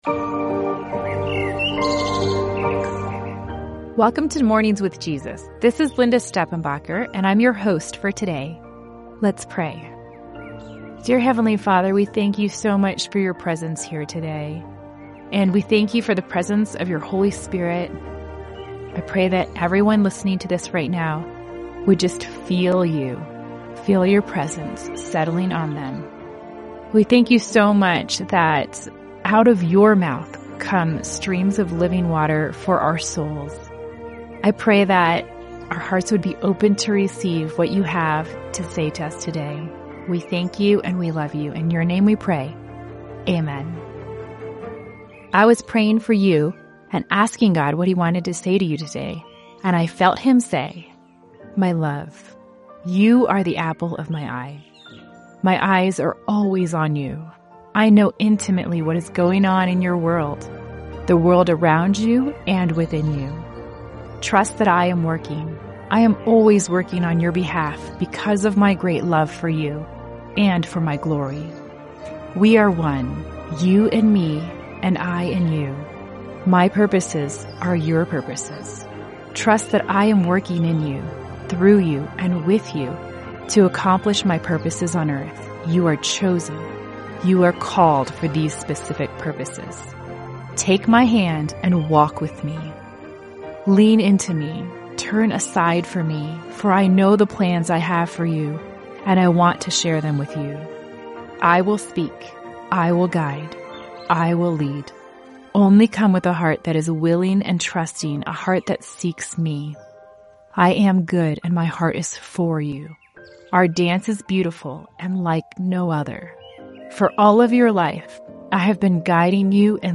🌿 Through gentle prayers, Scripture, and Spirit-led encouragement, Mornings with Jesus invites you to slow down, listen, and walk closely with the One who knows you best.